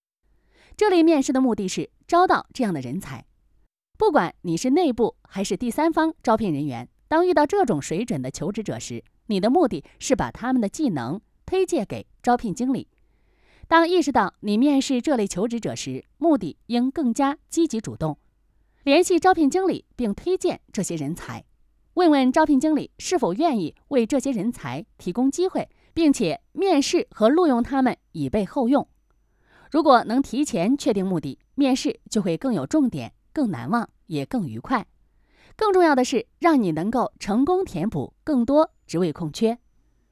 Chinese_Female_043VoiceArtist_4Hours_High_Quality_Voice_Dataset
Text-to-Speech